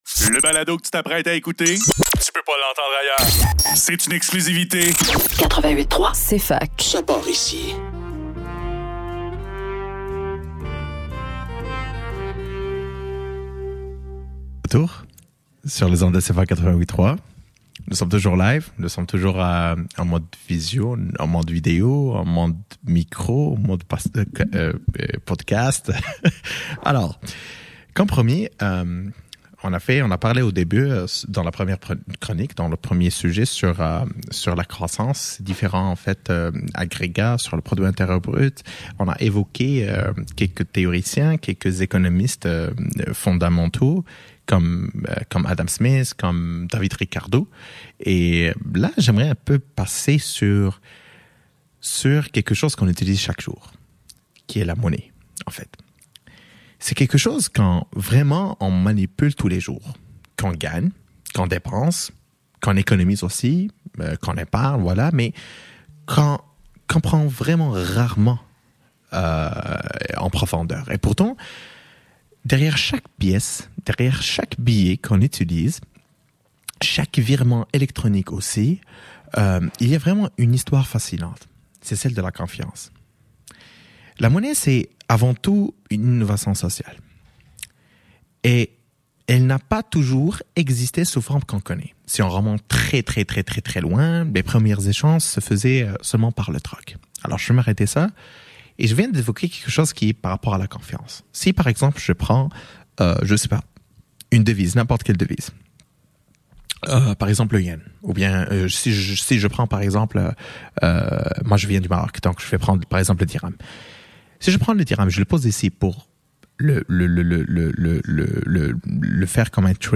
L'économie en mouvement – La monnaie : son histoire et sa construction – émission spéciale du Radiothon, 6 Novembre 2025